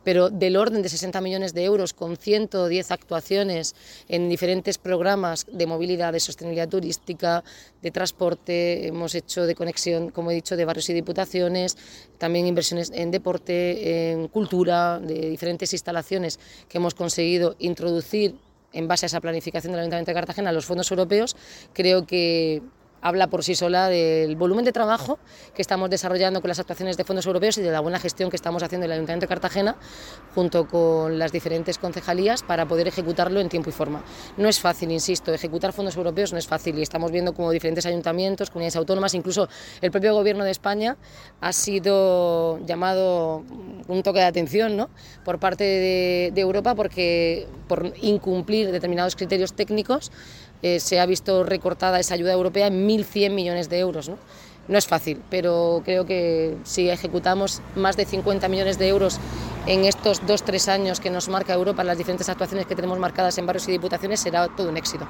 Audio: Declaraciones de Noelia Arroyo (Corte 1). (MP3 - 1,13 MB)